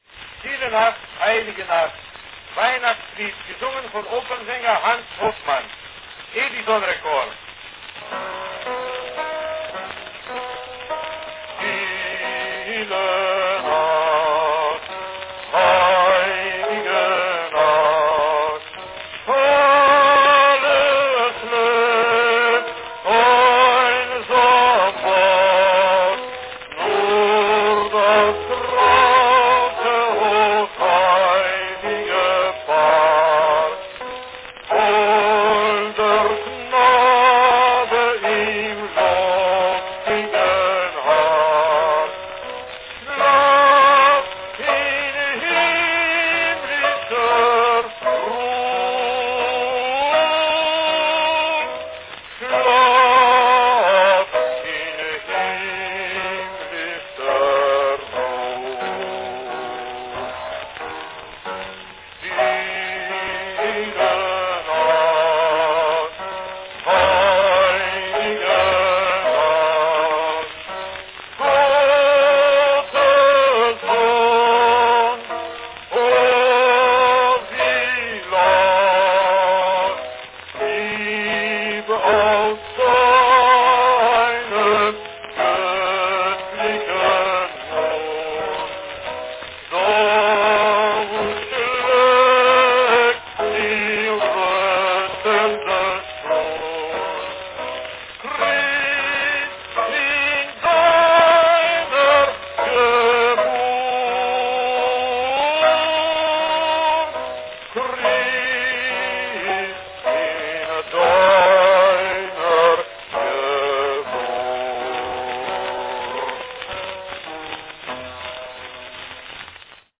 For the holiday season, a Christmas carol from 1902 Stille Nacht, Heilige Nacht (Silent Night, Holy Night).
Category Baritone
Enjoy an early Edison molded wax cylinder of this classic nineteenth century Christmas carol.